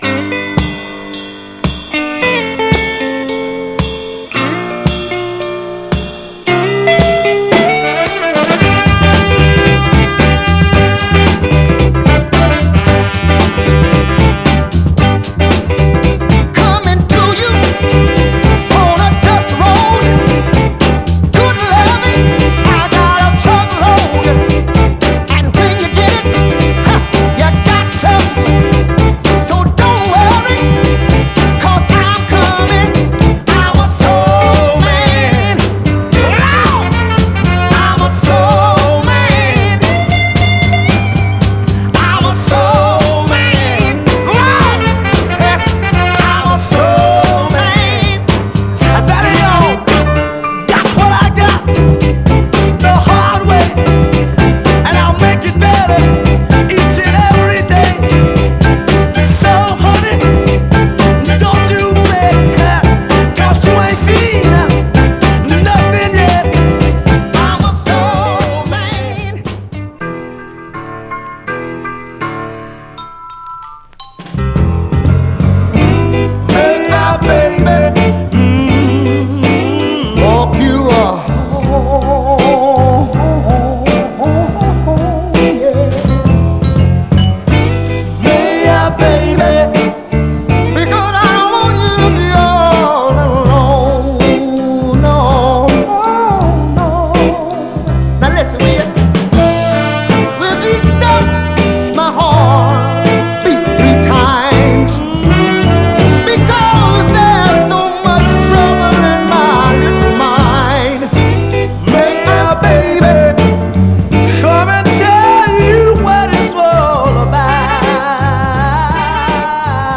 サザン・ソウルを代表するソウル・デュオ
盤はいくつか細かいスレ、薄いくすみ箇所ありますが、グロスがありプレイ良好です。
※試聴音源は実際にお送りする商品から録音したものです※